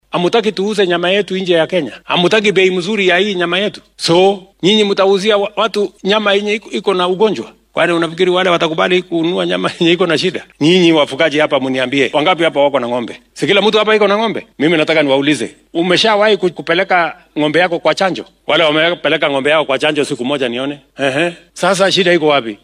Hadalkan ayuu madaxweynaha maanta ka jeediyay magaalada Kilgoris ee ismaamulka Narok.